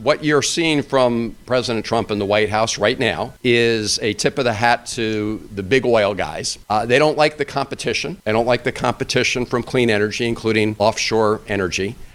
Maryland Energy Administration representatives along with federal lawmakers rallied at the offices of IBEW Local 24 in Baltimore to support offshore wind projects for the state.